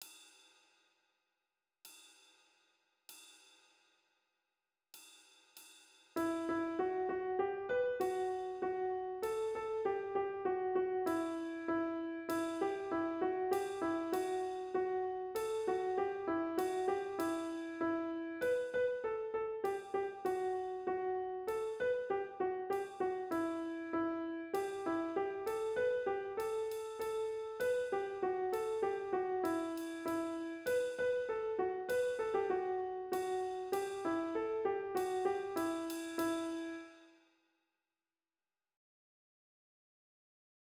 1. Kalevala Overture.wav